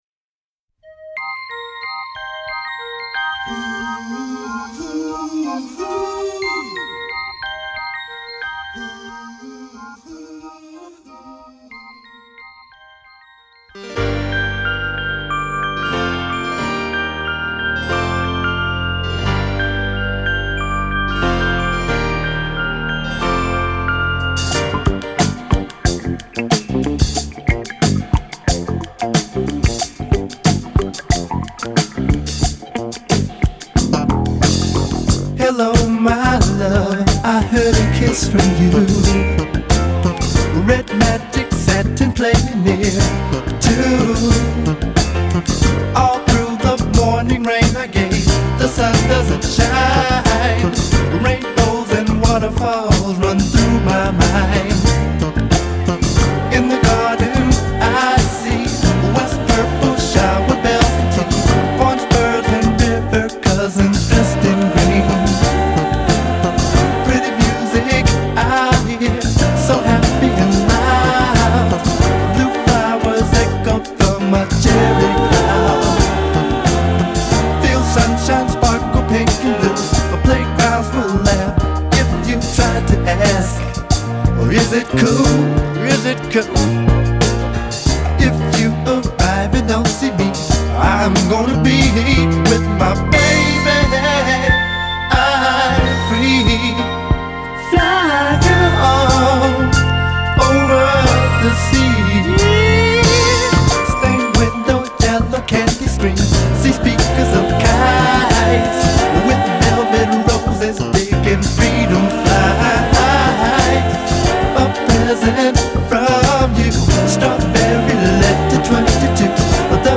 groovy R&B/soul songs